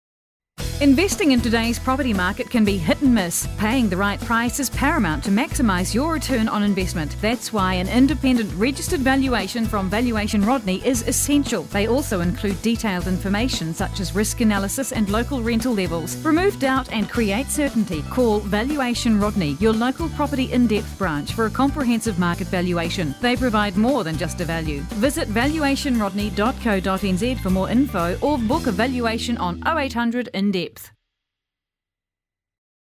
Radio Advertising